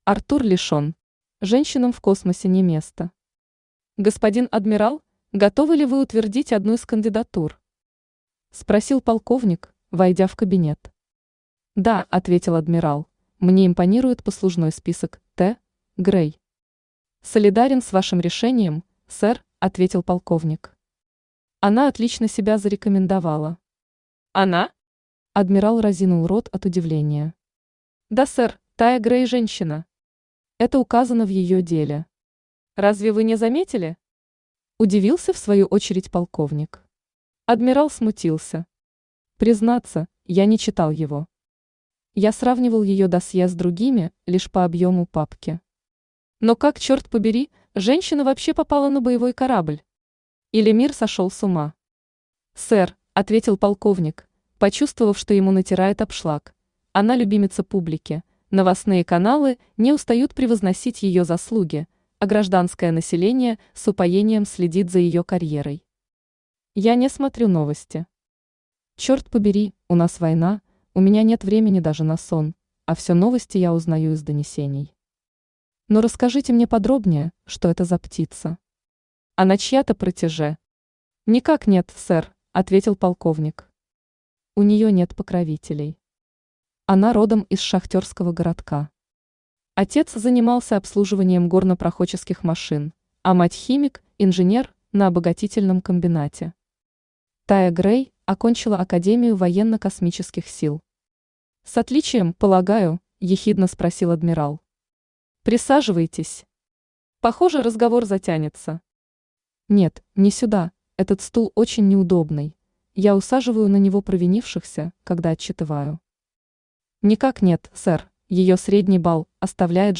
Аудиокнига Женщинам в космосе не место | Библиотека аудиокниг
Aудиокнига Женщинам в космосе не место Автор Артур Леонидович Лишен Читает аудиокнигу Авточтец ЛитРес.